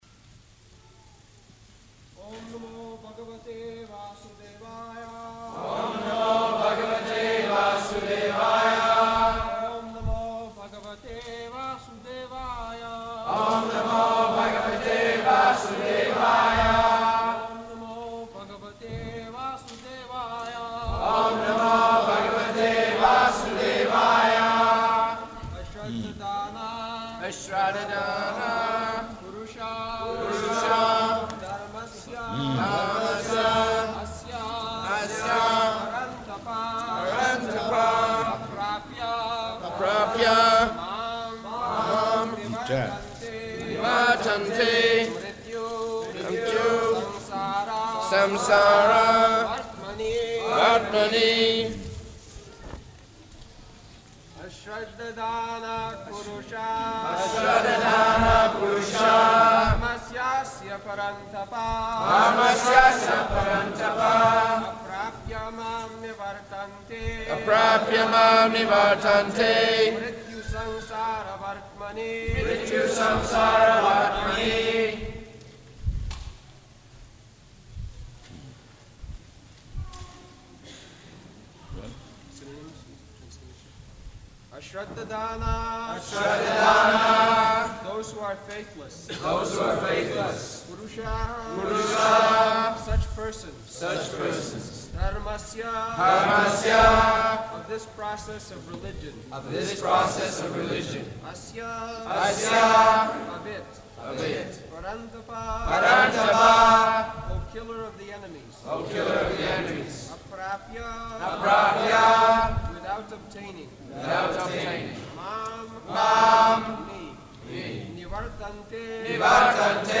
June 20th 1976 Location: Toronto Audio file
[devotees repeat] [leads chanting] Prabhupāda: Hmm.